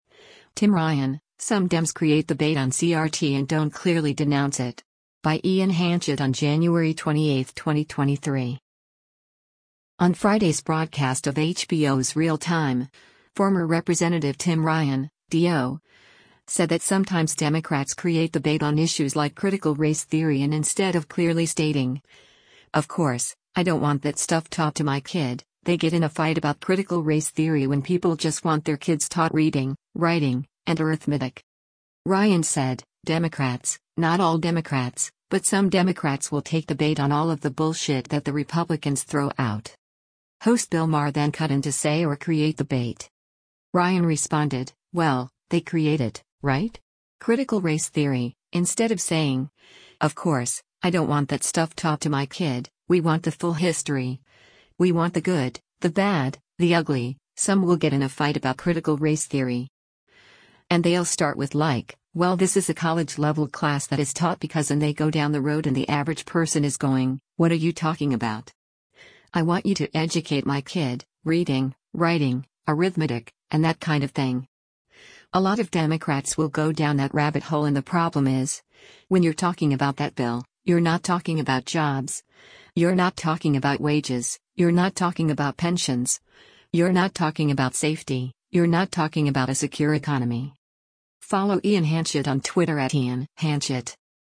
On Friday’s broadcast of HBO’s “Real Time,” former Rep. Tim Ryan (D-OH) said that sometimes Democrats “create” the bait on issues like Critical Race Theory and instead of clearly stating, “of course, I don’t want that stuff taught to my kid,” they “get in a fight about Critical Race Theory” when people just want their kids taught reading, writing, and arithmetic.
Host Bill Maher then cut in to say “Or create the bait.”